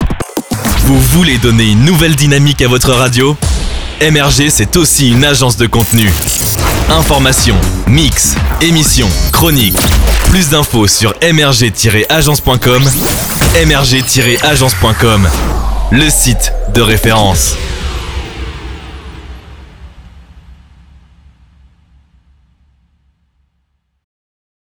medleys (mini-mix)
• Réalisés par nos DJ producteurs